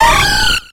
Cri de Feuforêve dans Pokémon X et Y.